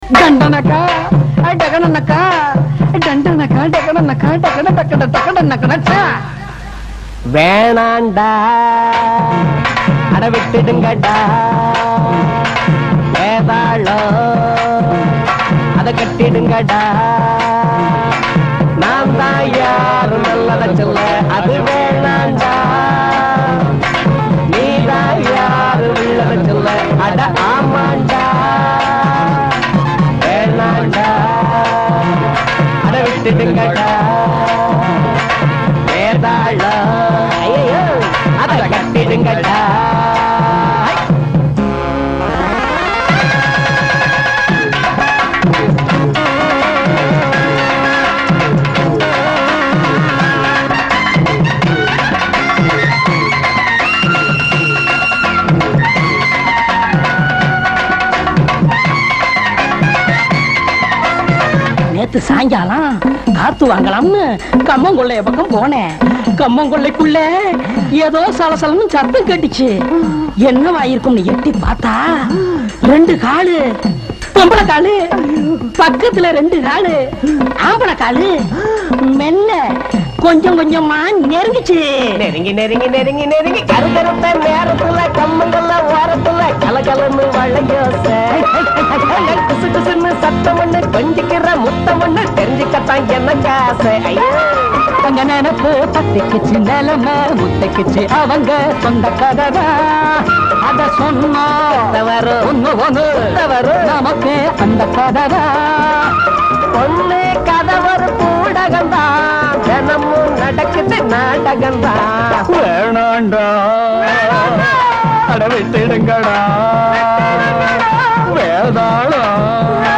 tamil movie songs